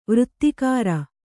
♪ vřttikāra